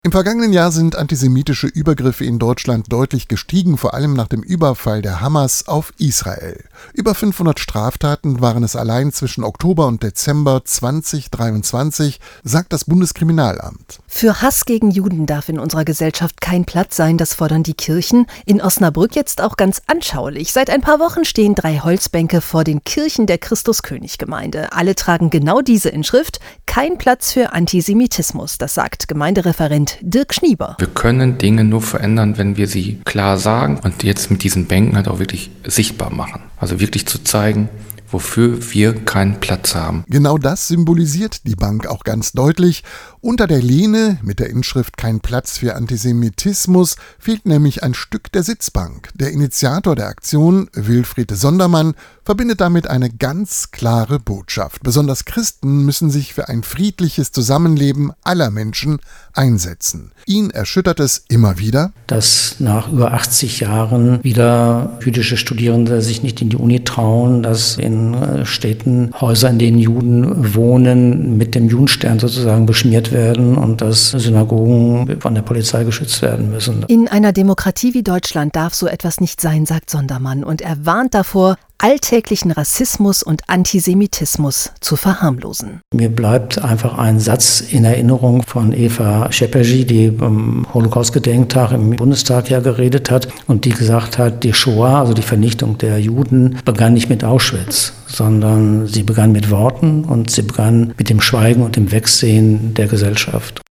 Einen Radiobeitrag über die Bänke mit den Aufschriften »Kein Platz für Antisemitismus« an Christus König, »Kein Platz für Hass und Gewalt« vor Heilig Geist und »Kein Platz für Diskriminierung« an der Franziskuskirche können Sie